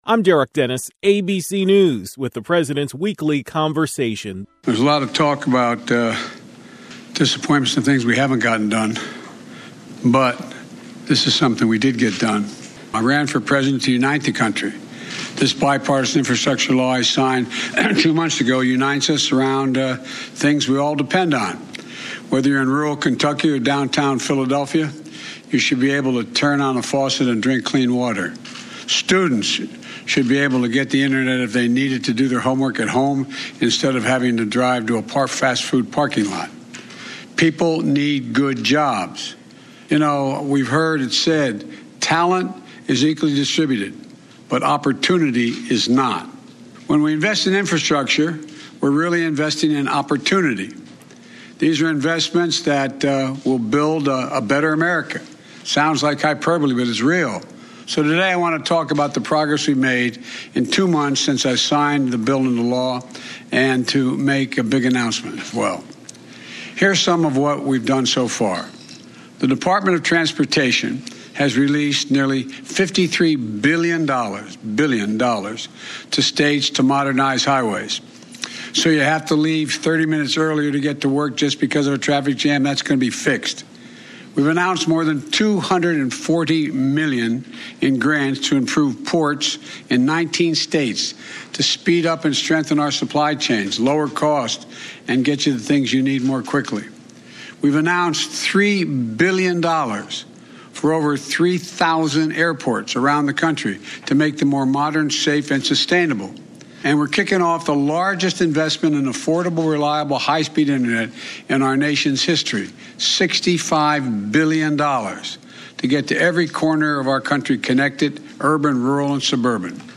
Here are his words: